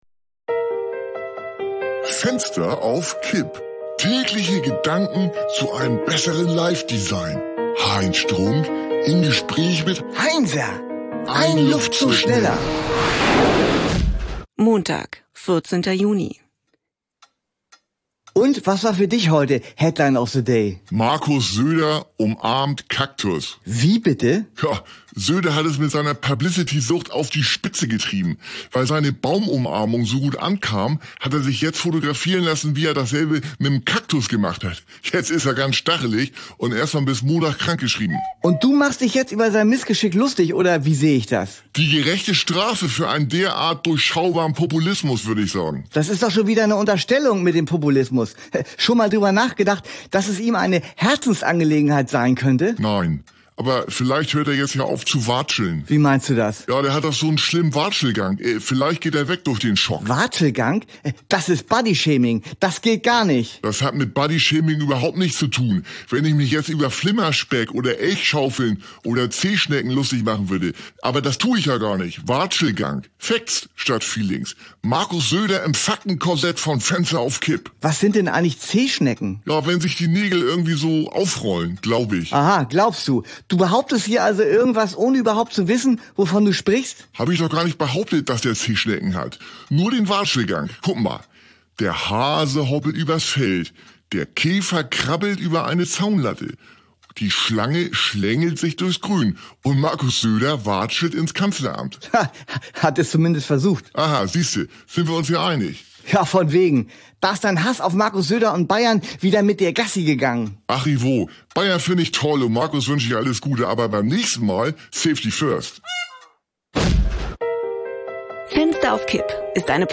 eine Audio-Sitcom von Studio Bummens
Comedy , Nachrichten , Gesellschaft & Kultur